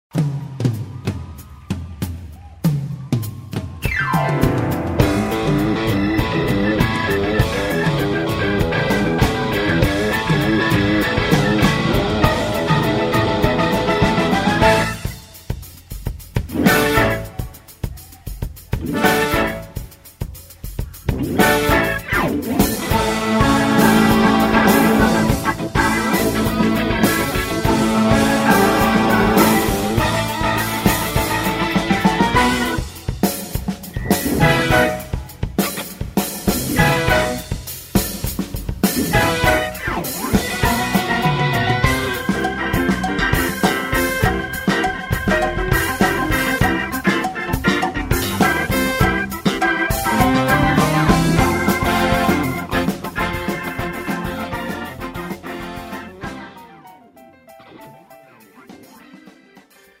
장르 뮤지컬 구분